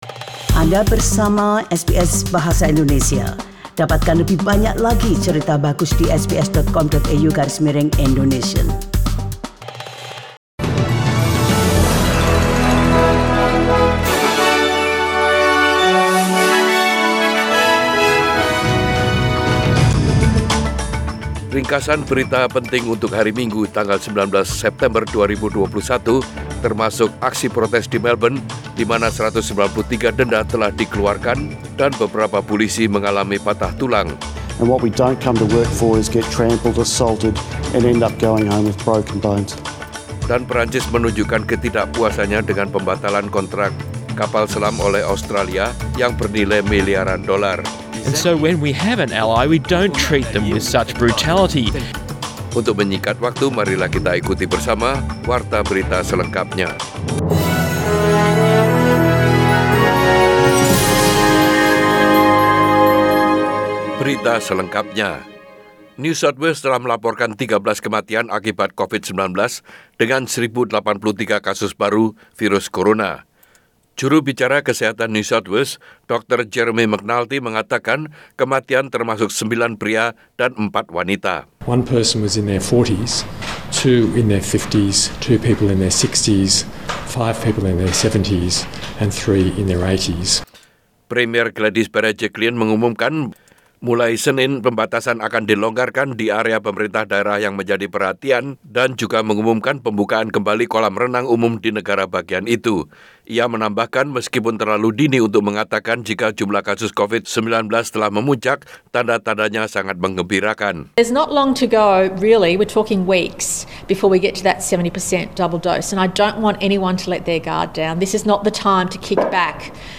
Warta Berita Radio SBS Program Bahasa Indonesia - 19 September 2021